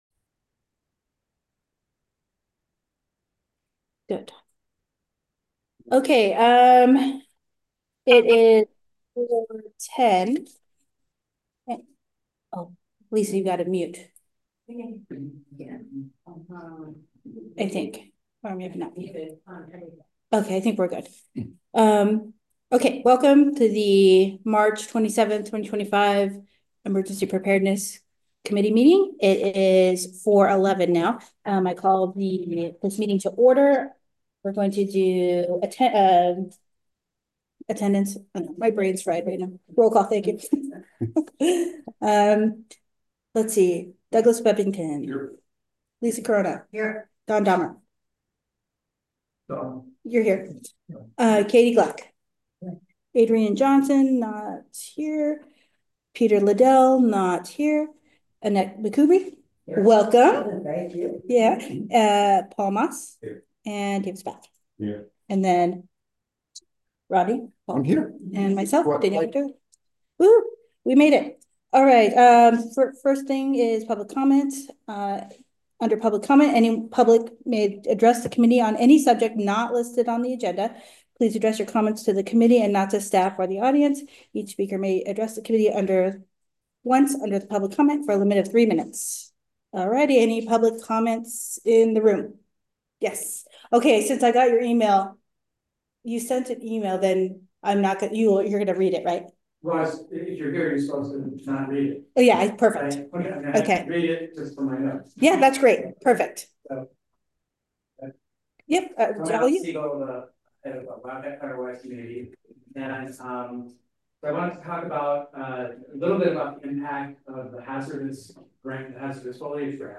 Emergency Preparedness Committee Meeting